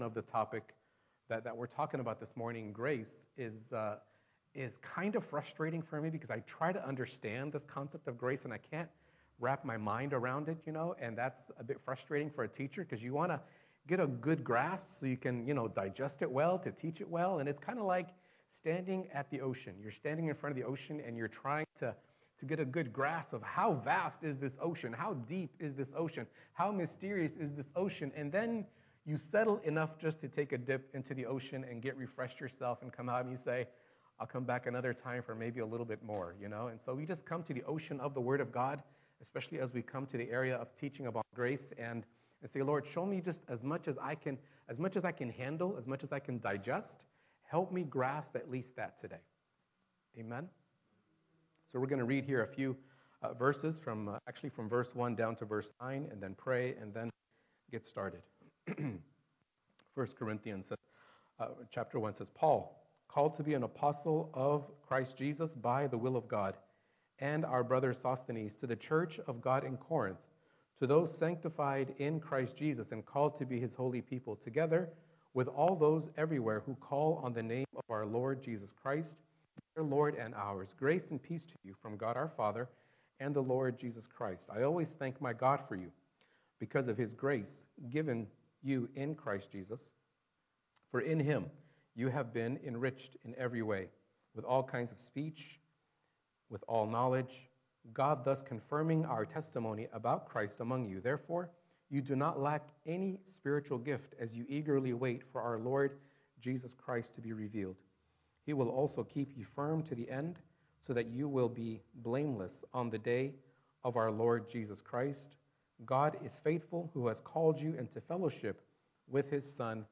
Discover God’s grace in this 1 Corinthians 1:3-9 sermon from Arise International Church, Norwalk, CA. grace forgives your past, equips your present, and secures your future.